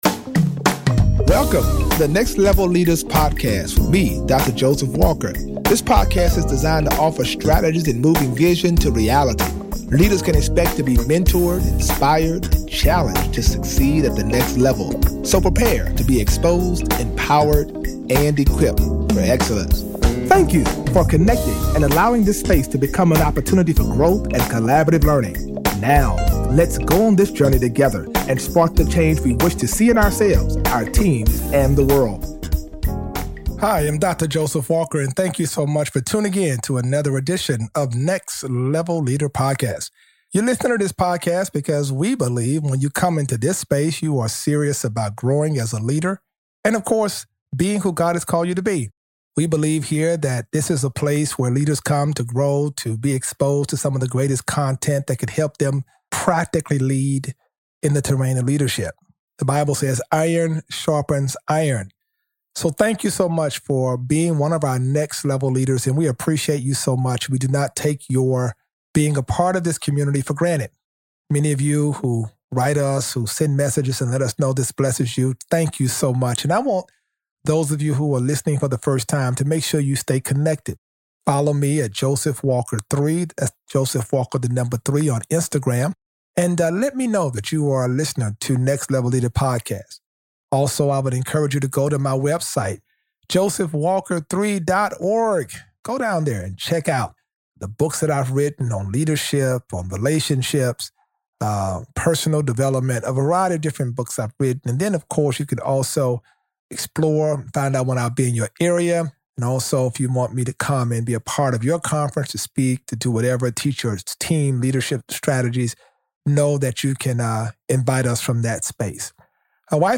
Each episode addresses the intersect between Christianity and the marketplace through conversations with successful leaders. Listeners will be mentored, inspired, and challenged to succeed at the next level.